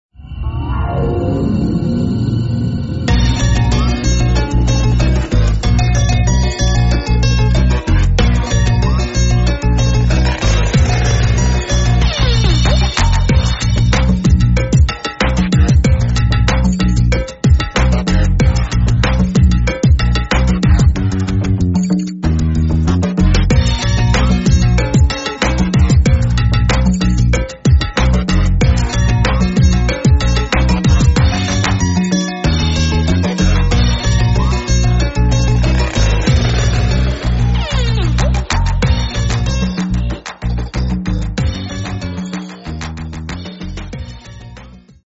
Estilo: Pop